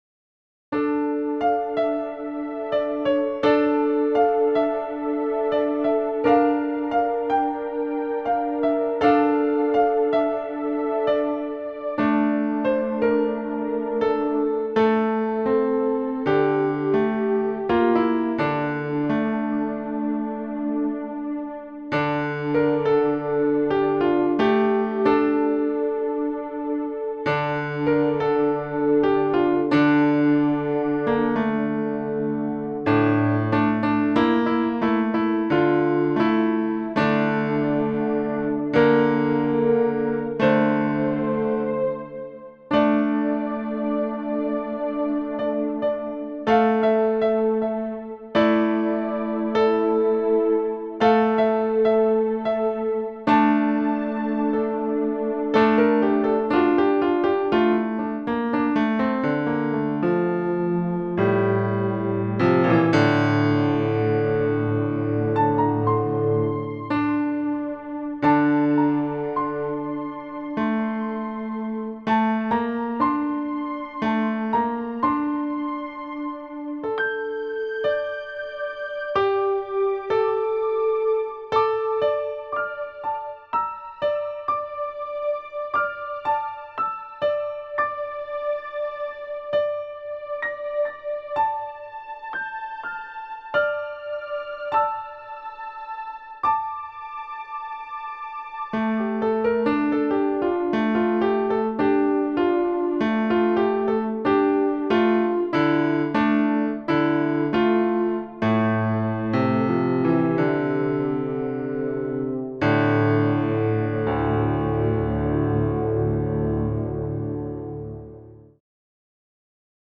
Posted in Classical Comments Off on